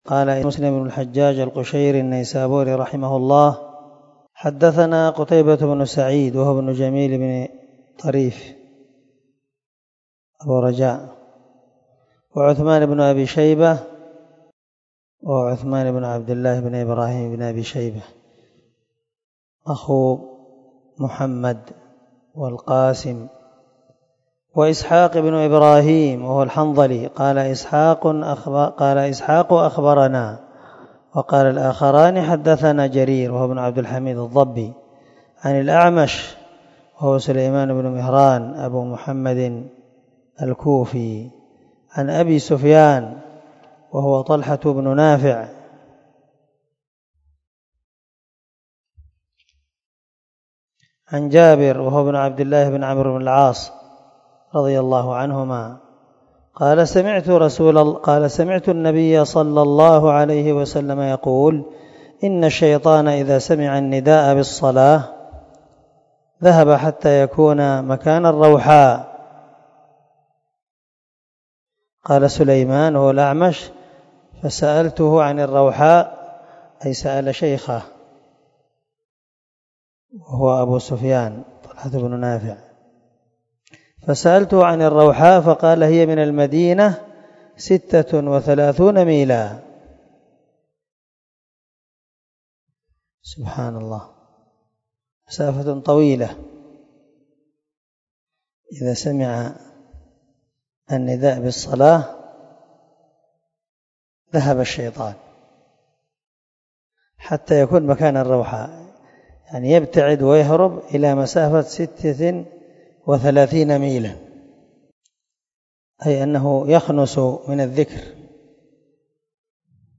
266الدرس 10 من شرح كتاب الصلاة حديث رقم ( 388 – 389 ) من صحيح مسلم